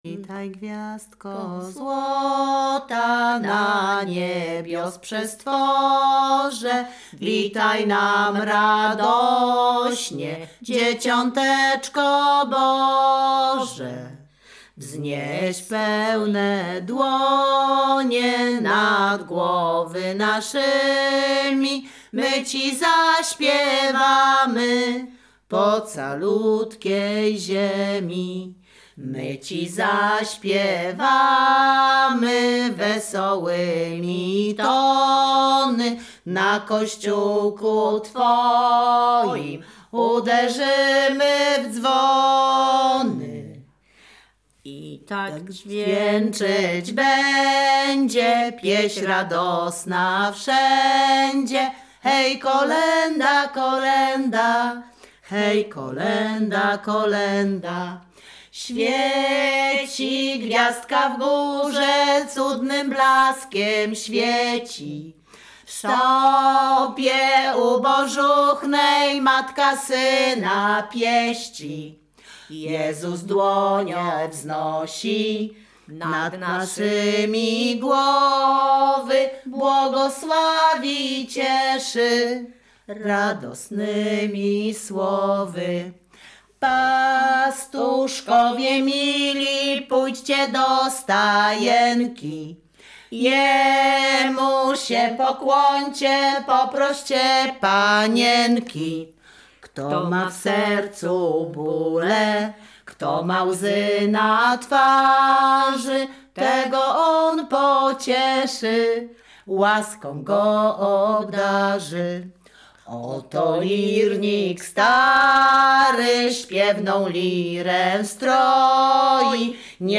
Śpiewaczki z Chojnego
województwo łódzkie, powiat sieradzki, gmina Sieradz, wieś Chojne
Kolęda